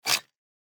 main_ui_btn.mp3